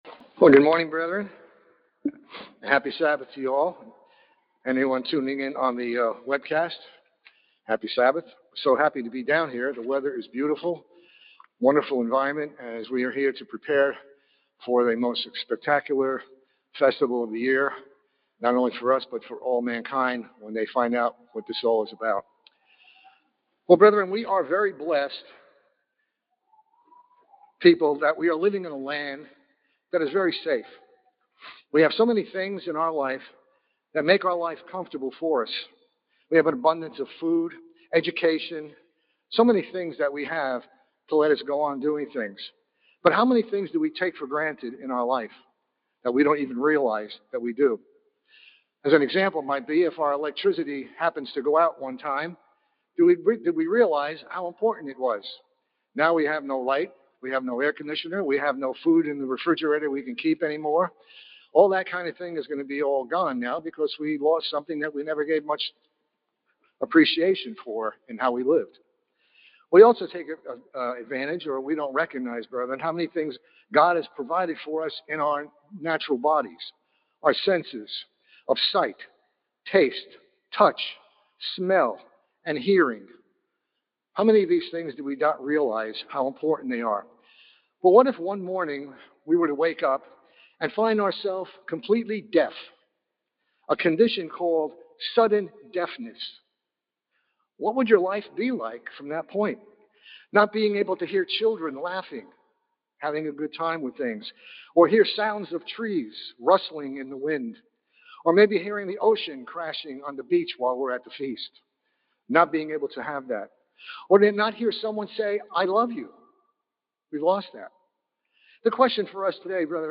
This sermon was given at the Panama City Beach, Florida 2022 Feast site.